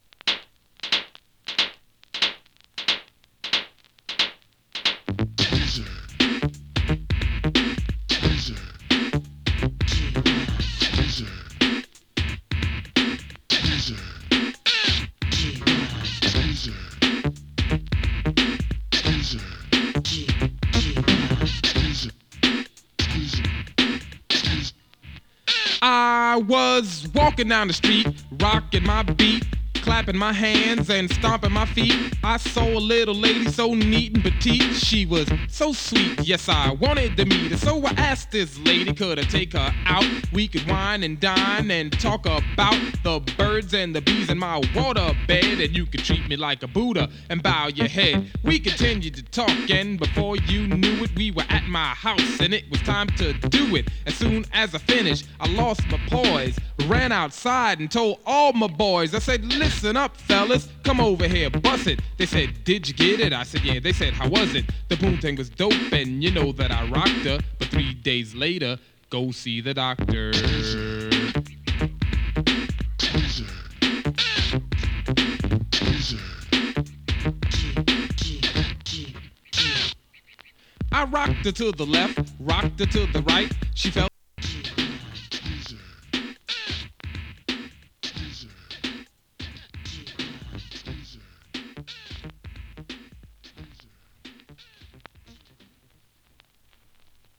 エレクトロ オールドスクール
HIPHOP ジャケ付き7inchシングル！
[2version 7inch]＊音の薄い部分で軽いチリパチ・ノイズ。